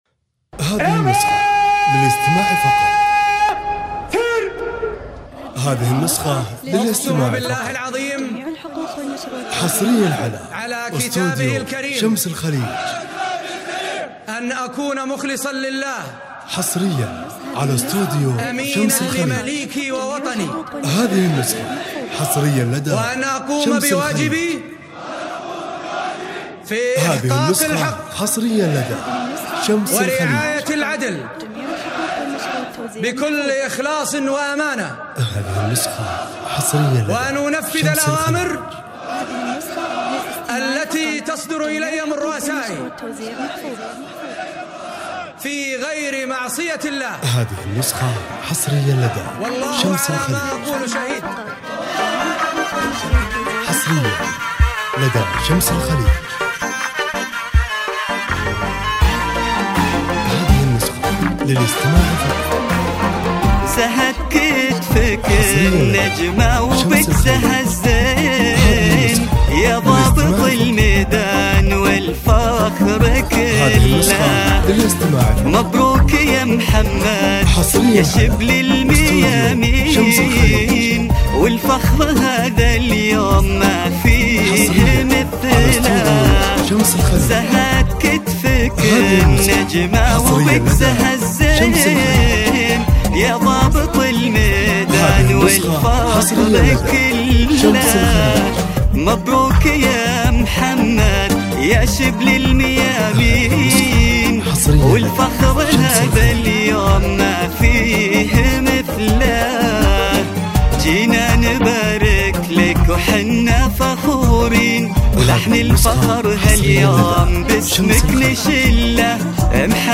زفة تخرج عسكرية موسيقية فخمة